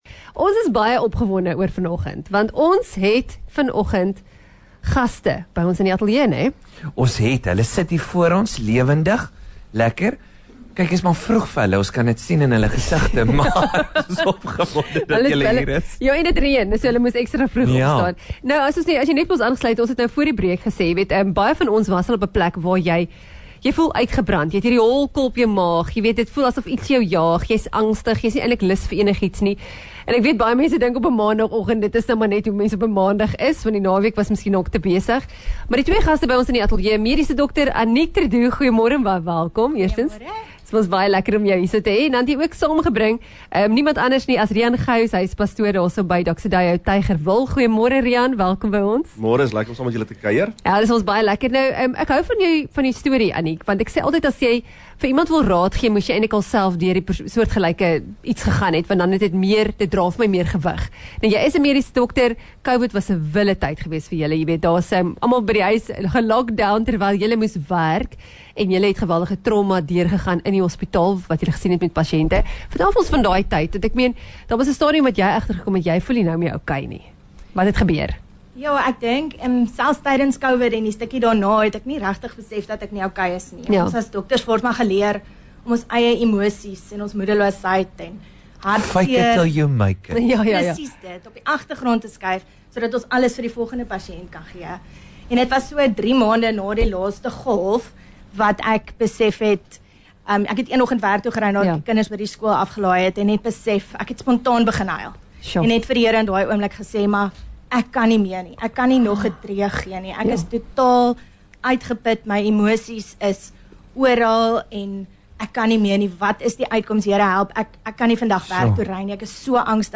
Sy het gesels met die Real Brekfis-span (06:00-09:00).